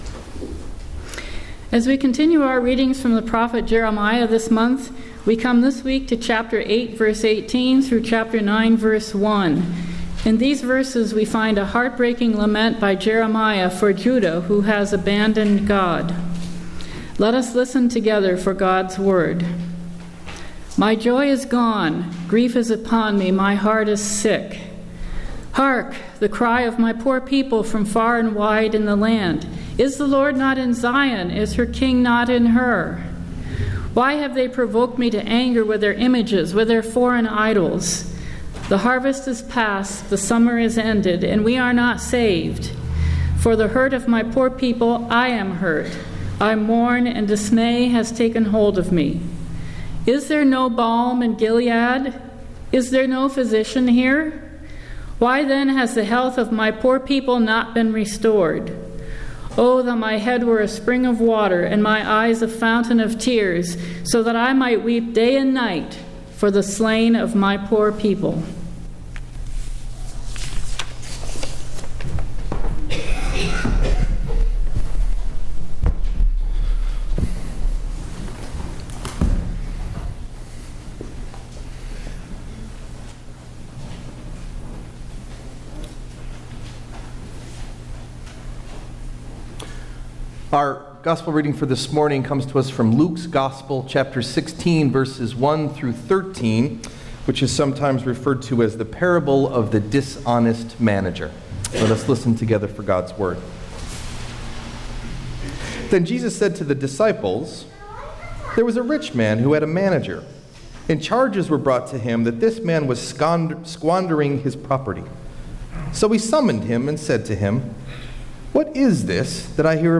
Message Delivered at: The United Church of Underhill (UCC and UMC)